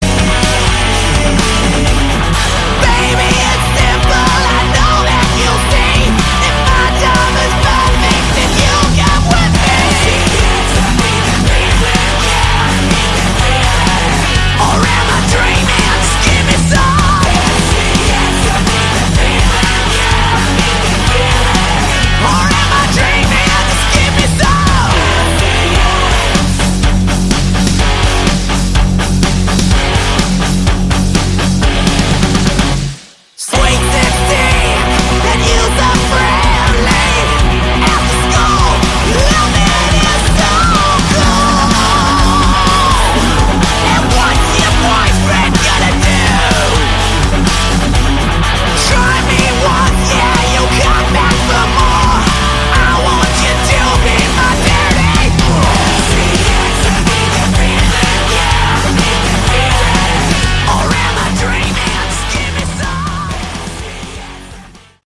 Category: 80s Hard Rock